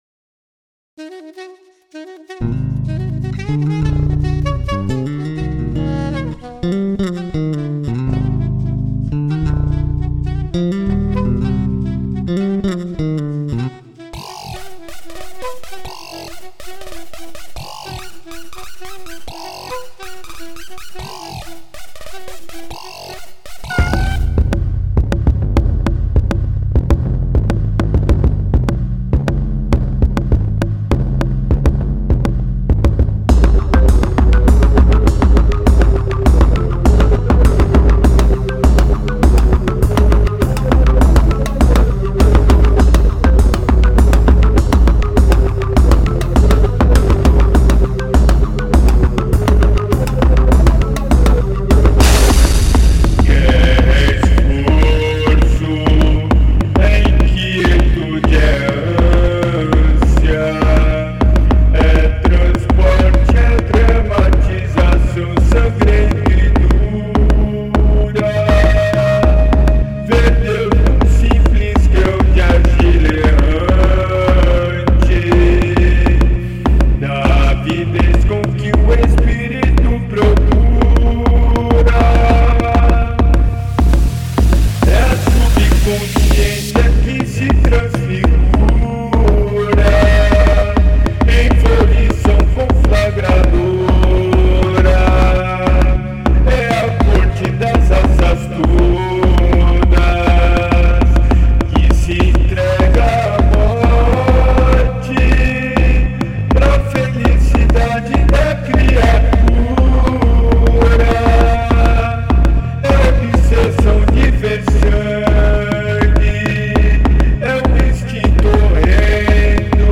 EstiloExperimental